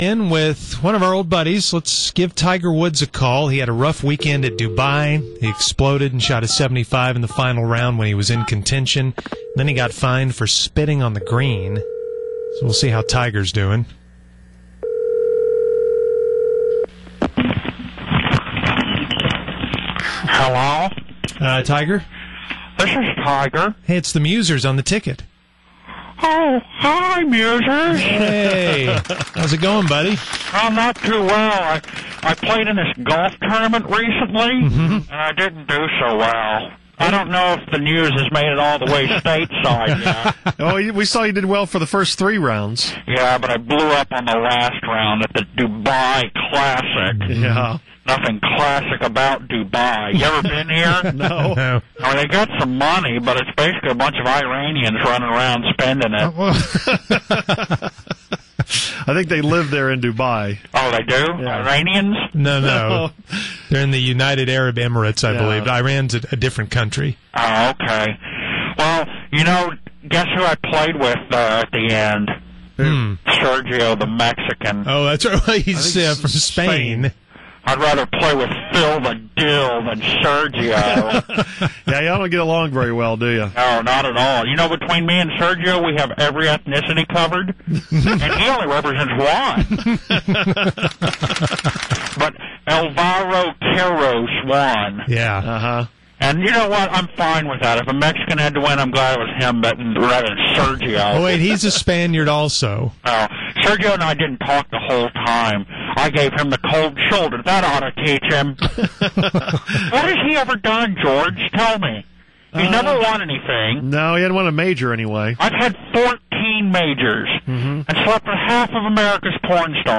Musers talk to the fake Tiger about his performance at the Dubai Classic.